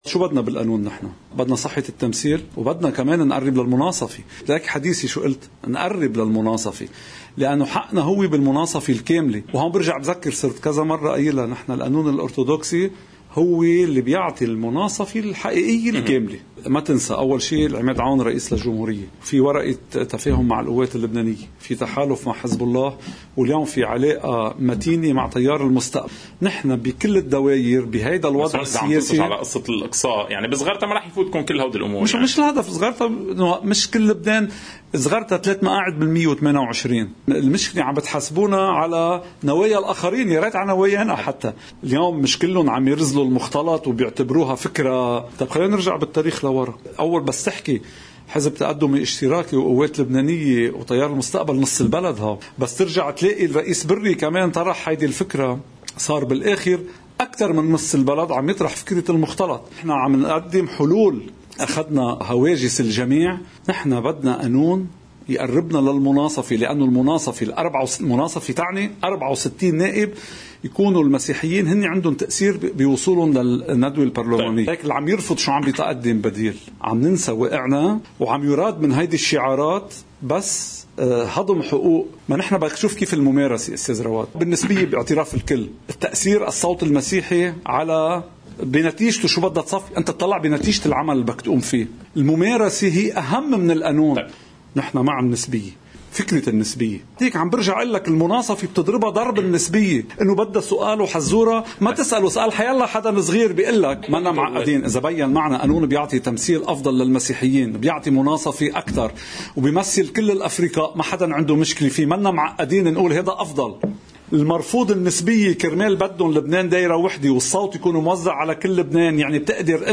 مقتطف من حديث النائب السابق سليم عون لقناة الـ”OTV” ضمن برنامج “يوم جديد”: